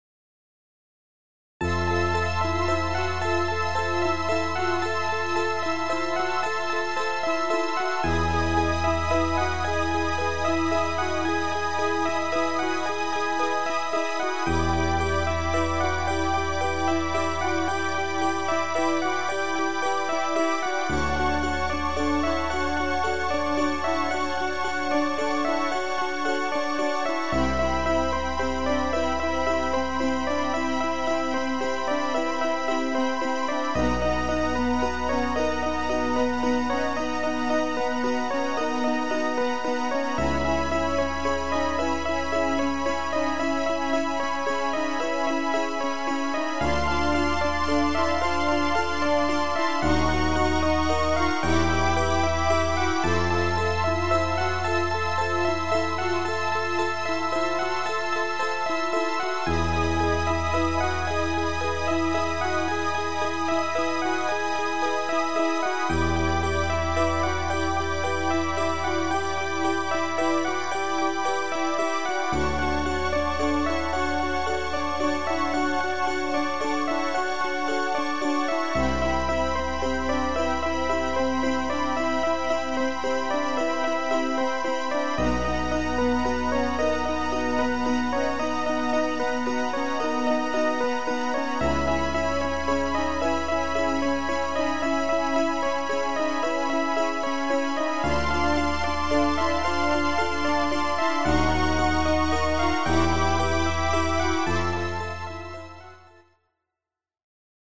1:48 10.0KB 手癖が非常に強い曲。
こっちは海の中に潜った時の浅い方。